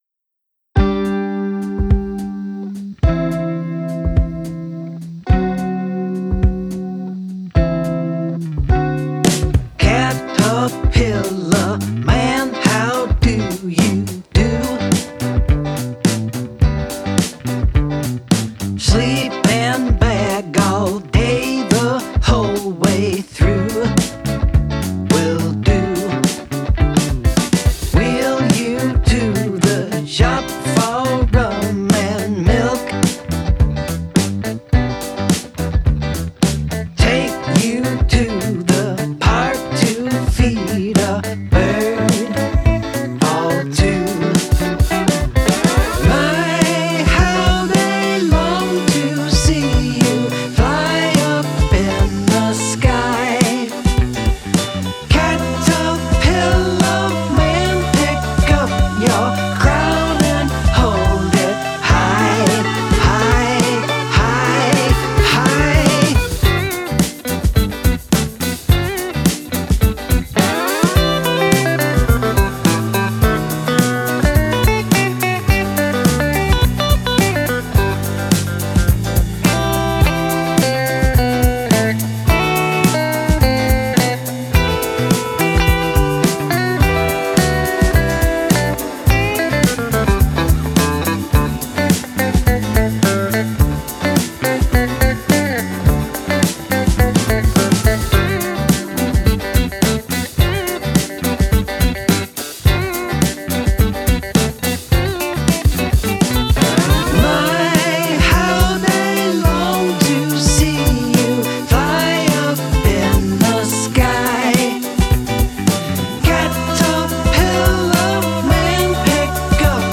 Listening to the two tracks, I think the problem is more that the snare level is high relative to the other drums. It really pops out.
I found the snare was a little thin too so I addressed that a little and at the same time saw to the level.